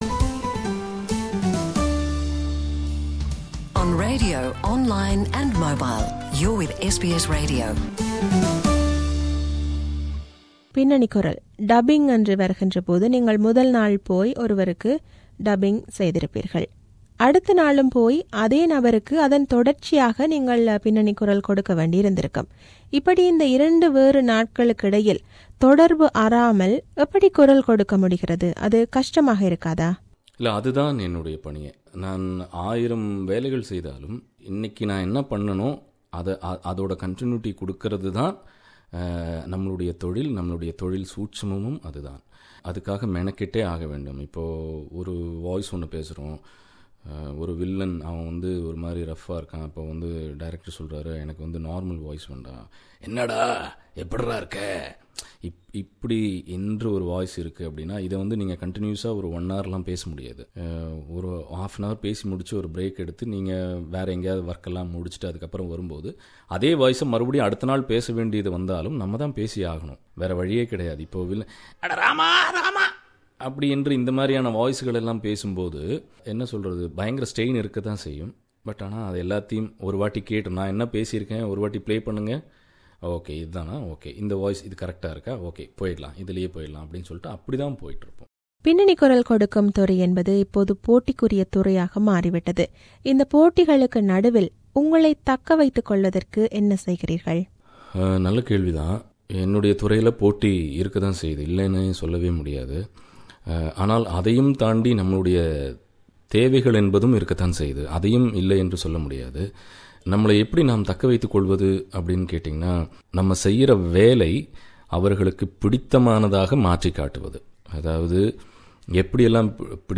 This is an interview with him.(Part 02)